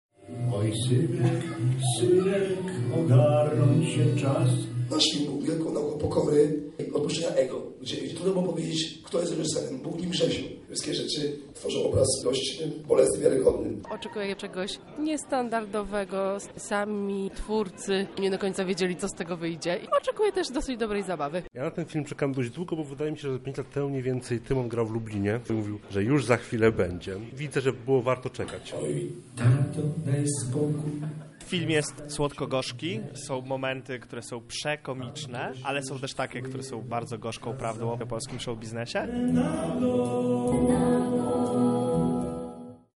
– mówi Tymon Tymański – muzyk i scenarzysta: